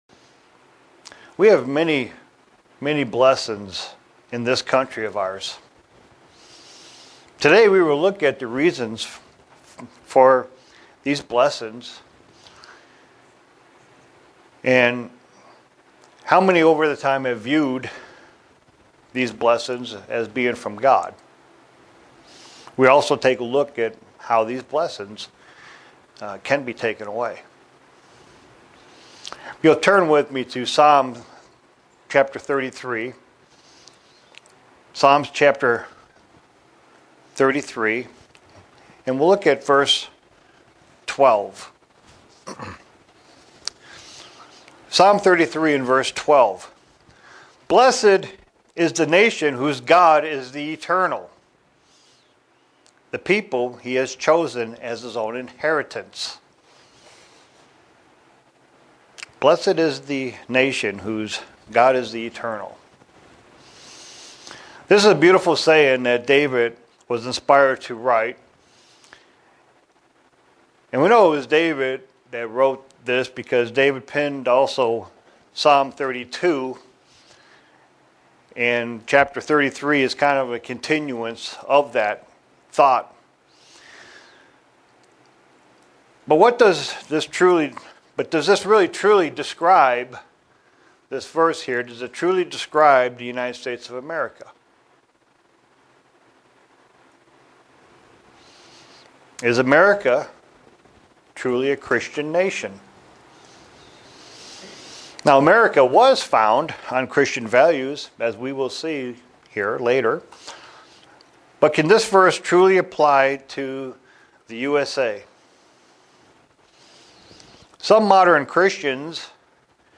Print The reason we have Blessings and how they can be taken away. sermon Studying the bible?